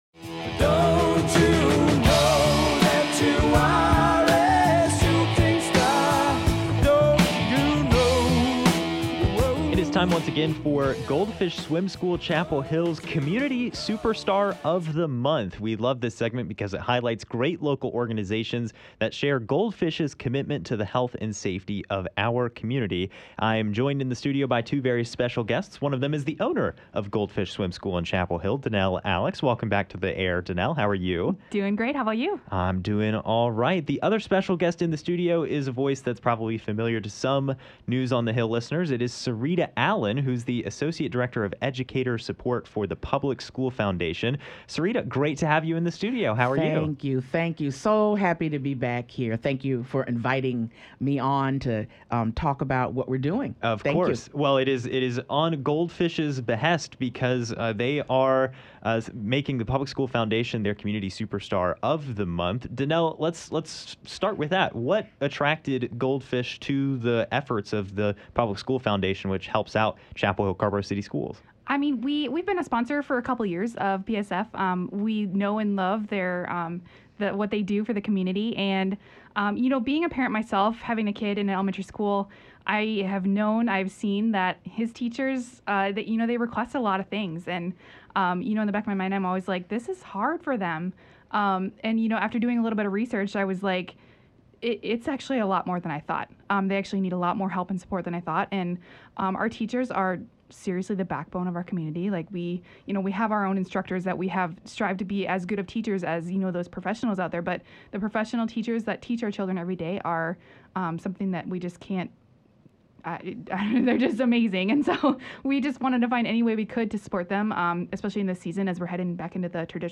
Listen above for the full conversation about the work PSF is doing, as well as some news about what Goldfish has going to help!